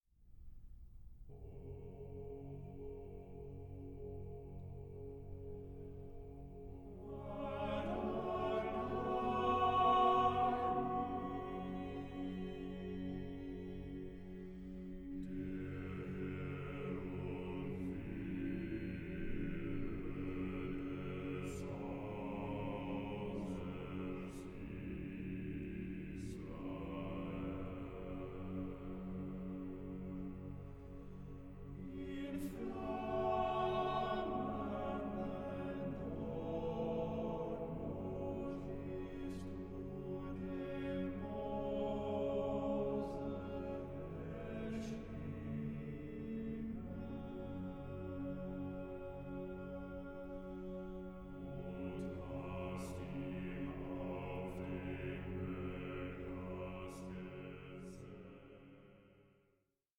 contemporary Scandinavian and Baltic choral music